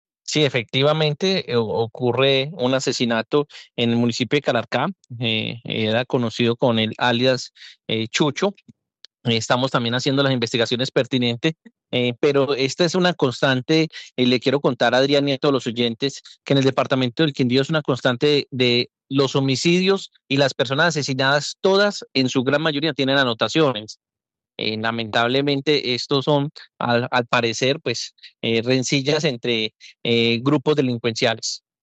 Jaime Andrés Pérez, secretario interior del Quindío